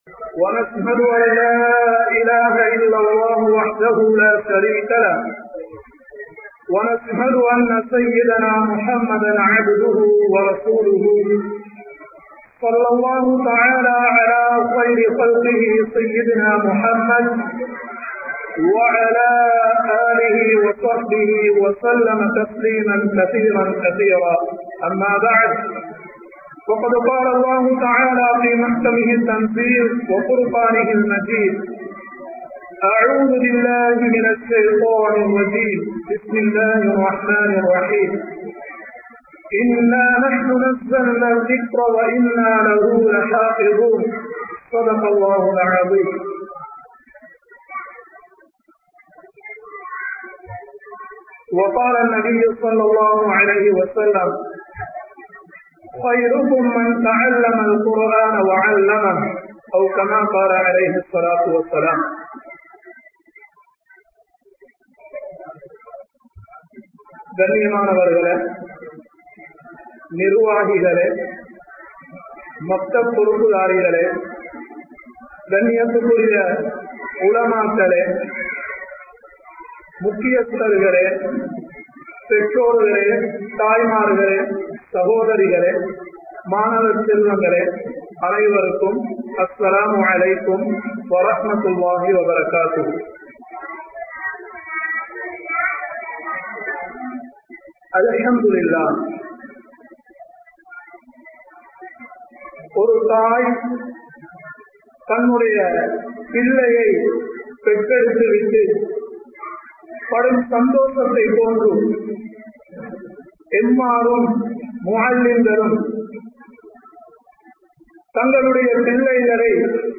Makthabin Avasiyam(மக்தபின் அவசியம்) | Audio Bayans | All Ceylon Muslim Youth Community | Addalaichenai